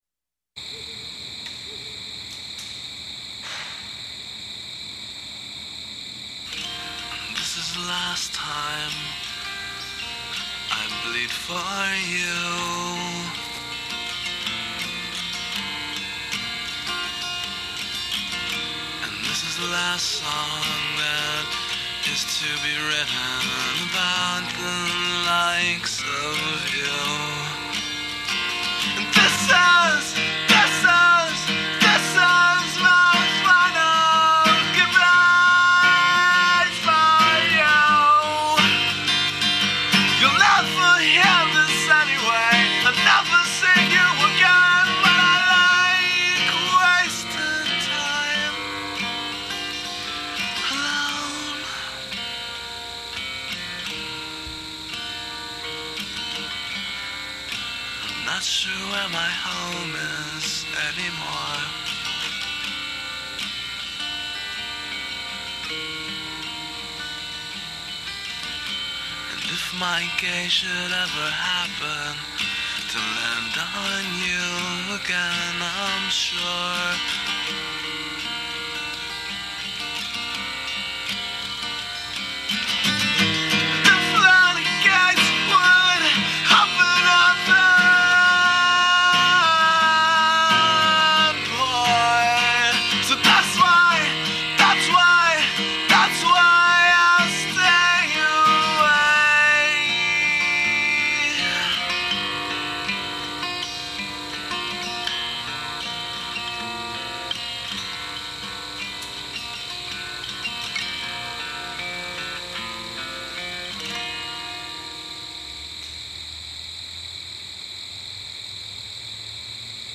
pop-punk 3 piece